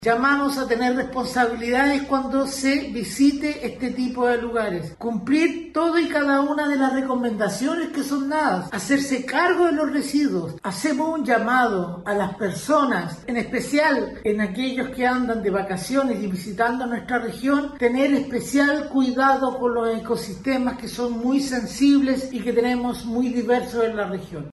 En paralelo, el seremi de Medioambiente de la Región de Valparaíso, Álex Galleguillos, reforzó el llamado a la responsabilidad turística y pidió especial cuidado con ecosistemas “muy sensibles“, junto con hacerse cargo de los residuos.
cu-playa-montemar-seremi.mp3